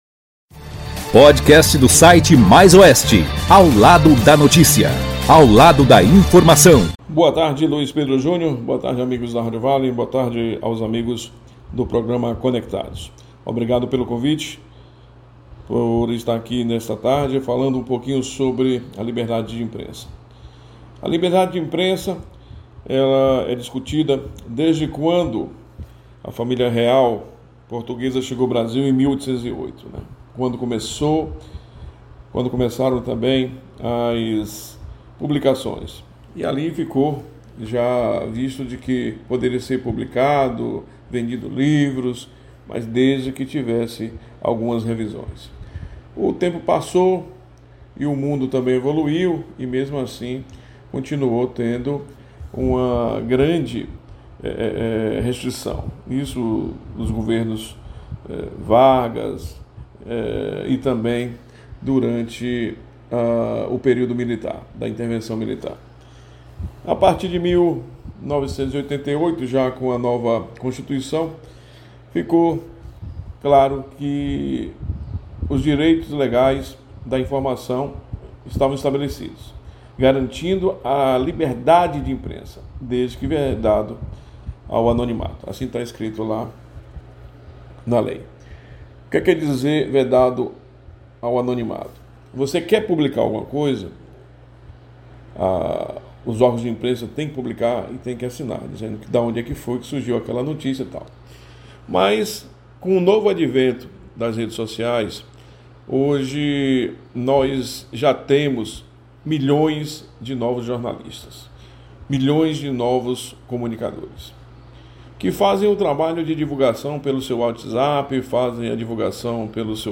O Site Mais Oeste participou do programa Conectados da Rádio Vale para falar sobre a o dia 07 de Junho, o Dia da Liberdade de Imprensa no Brasil.